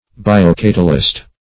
Meaning of biocatalyst. biocatalyst synonyms, pronunciation, spelling and more from Free Dictionary.
biocatalyst.mp3